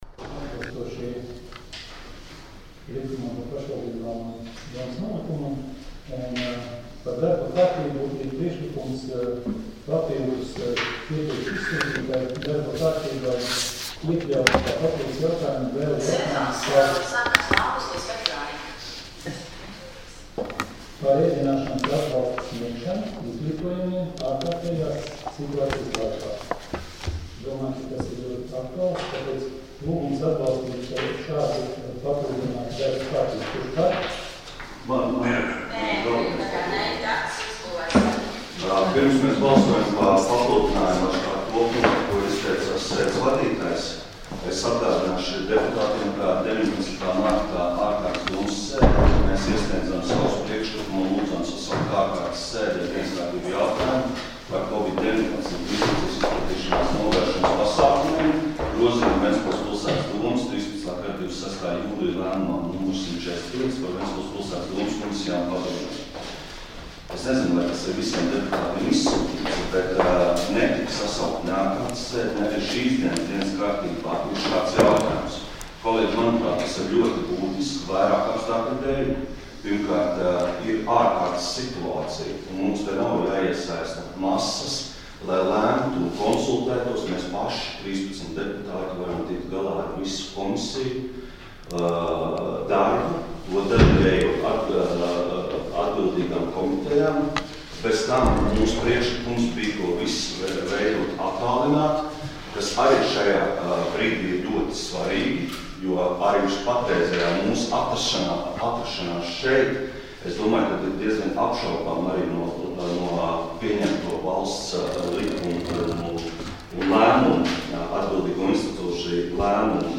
Domes sēdes 13.11.2020. audioieraksts